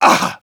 Engineer_painsharp04_de.wav